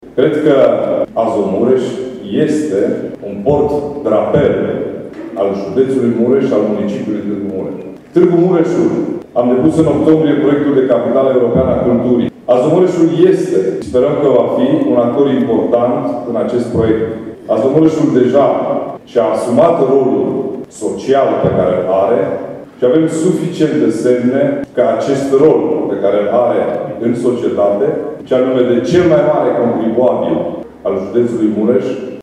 Președintele Consiliului Județean Mureș, Ciprian Dobre, a subliniat importanța acestei investiții pentru dezvoltarea județului.